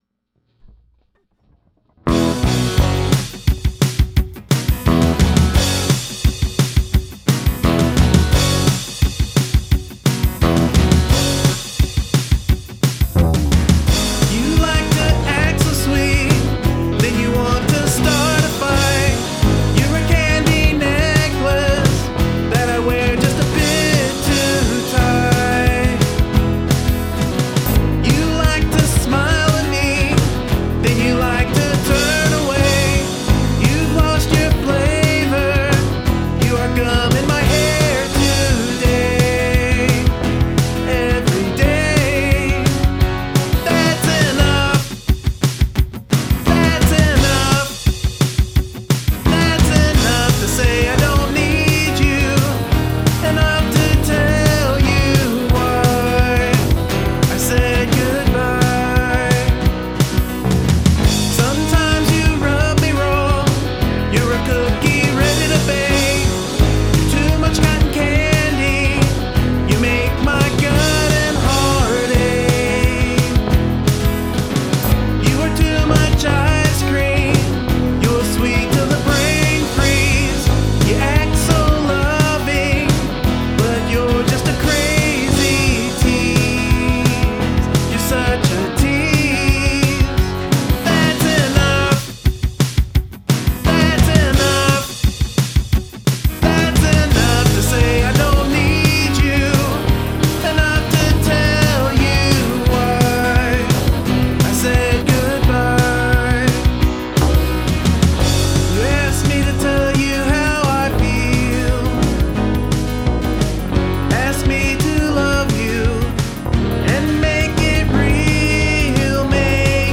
Excellent pop driven tune, really catchy too, really up there with your usual high standard, loved the listen!!
magic - starts like a good old kicking punk choon and mellows into the angst of... well we all know what wimmin do to ya......